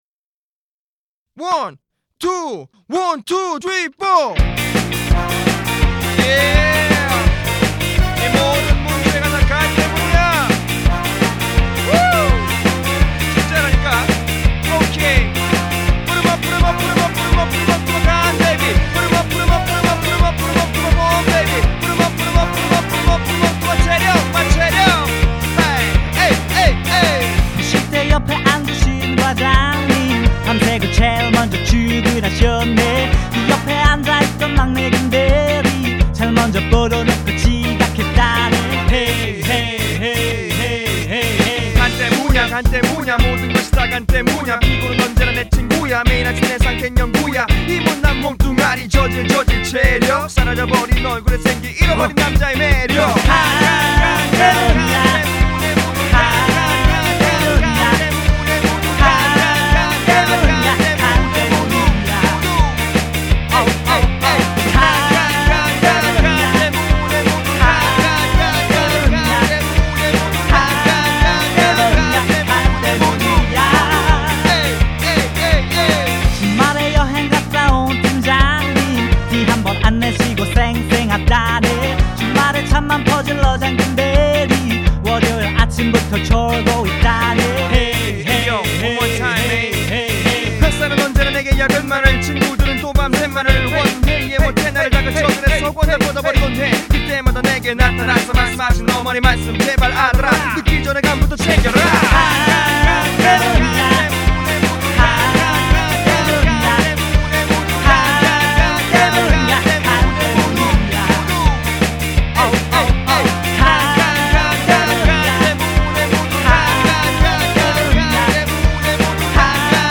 8인조로 인디에서 활동하고 있는 하이브리드 뮤직밴드
맨땅에 해딩하는 기분으로 아이디어를 짜다가, 결국, 'Ska Funk'를 기본으로 신나게 만들게 되었습니다.